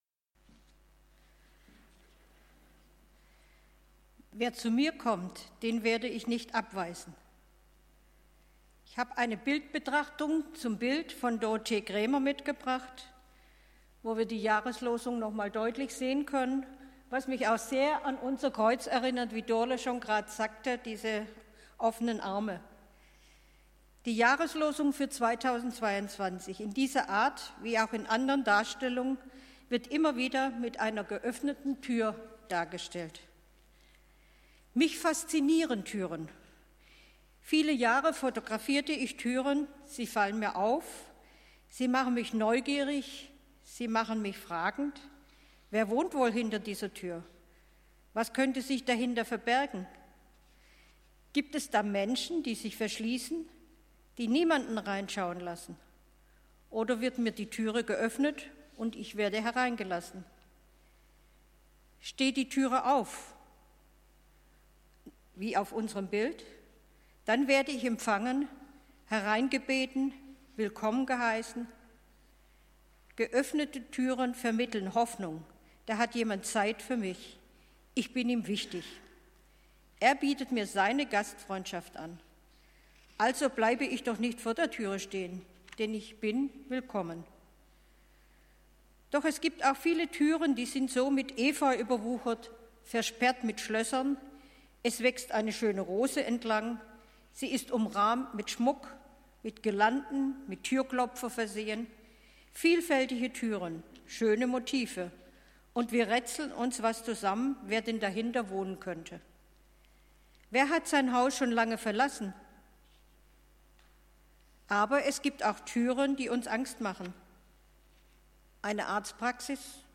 Gottesdienst zur Jahreslosung
Jan. 17, 2022 | Predigten | 0 Kommentare